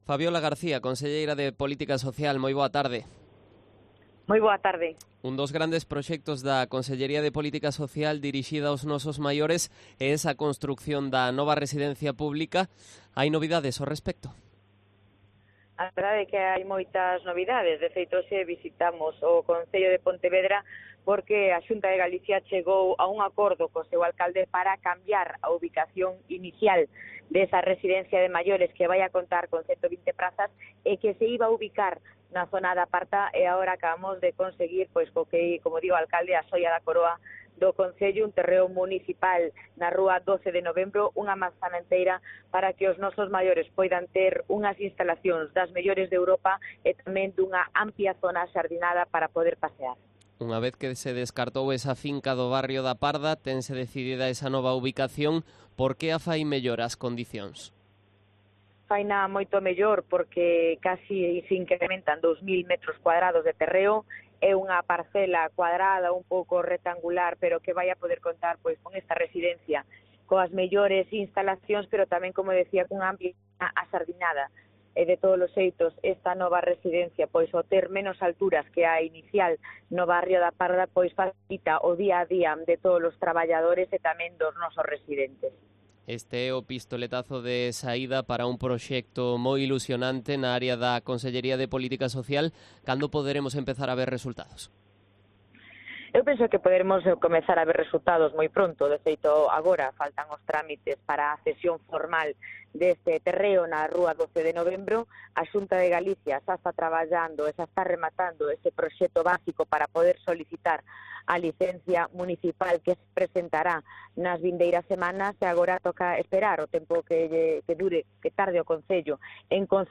Entrevista a Fabiola García, conselleira de Política Social